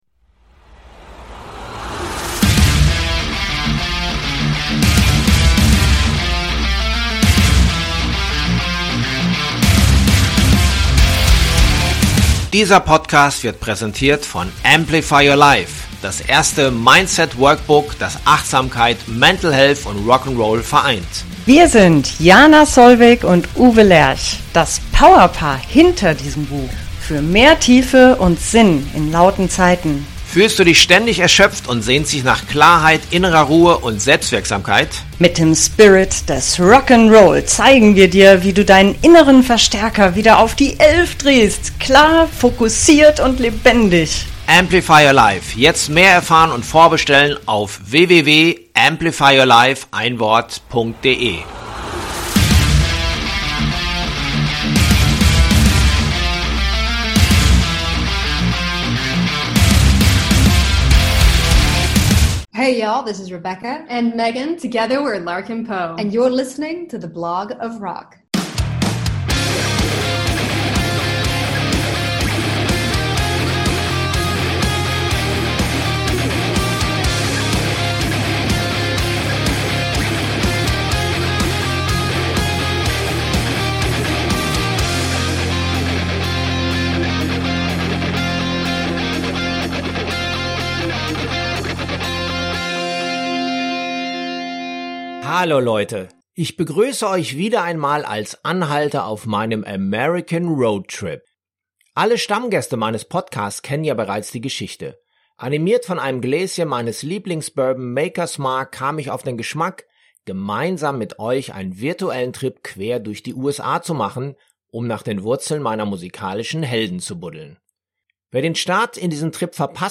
Ihr Sound ist geprägt von traditionellem Blues, Southern, Roots-Rock - mit viel Slide-Gitarre und einem tollen Harmonie-Gesang.